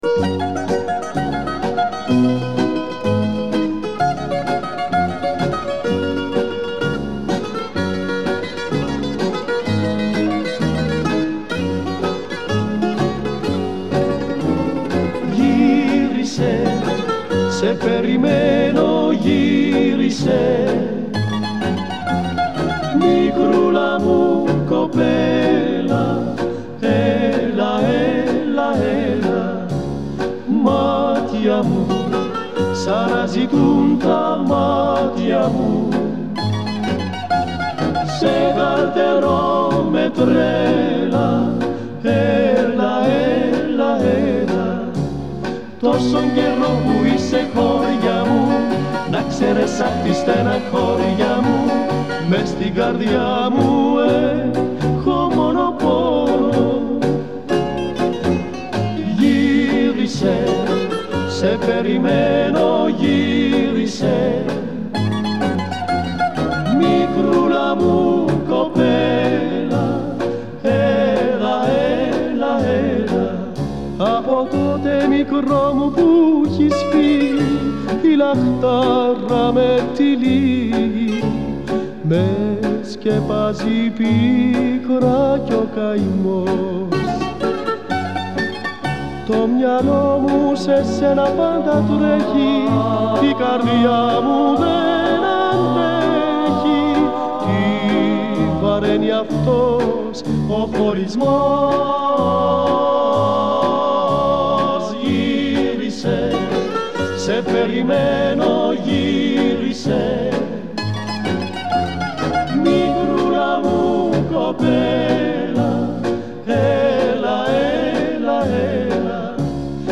Музыкальное трио из солнечной Греции.
Музыкальный стиль Laika.
Genre: Folk, Instrumental